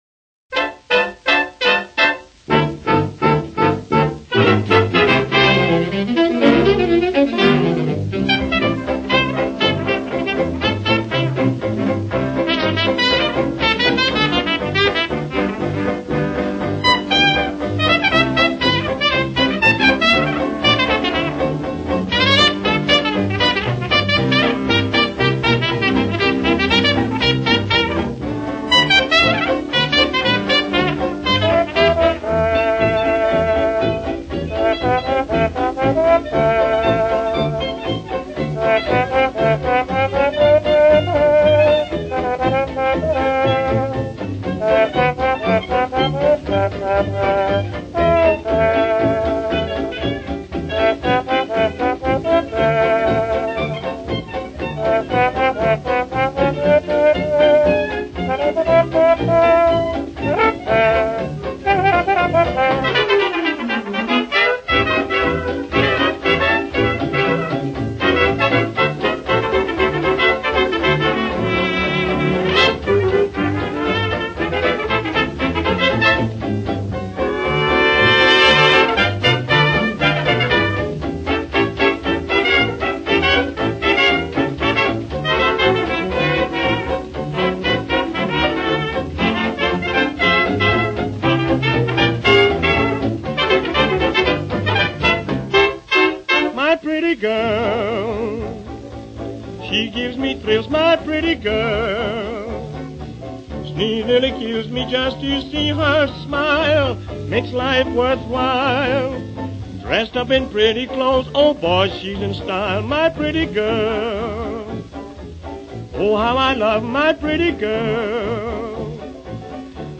orchestra